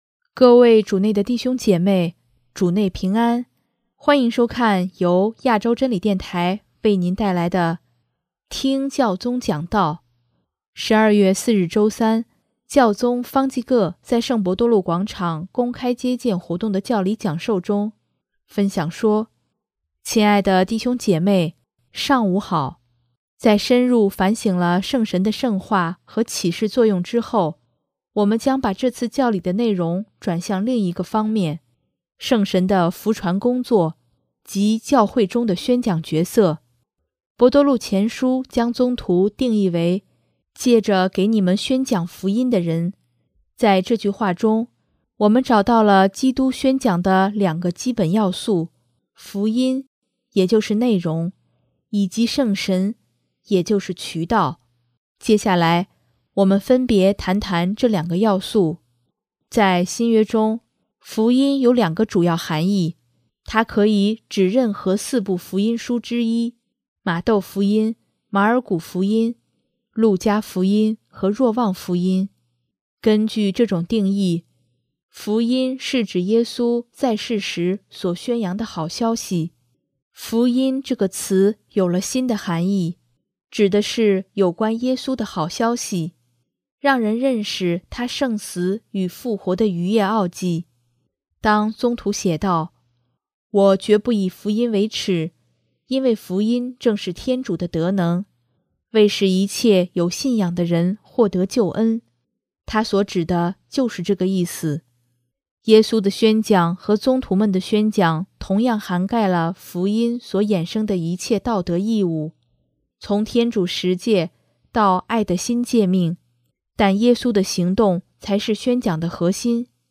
12月4日周三，教宗方济各在圣伯多禄广场公开接见活动的教理讲授中，分享说：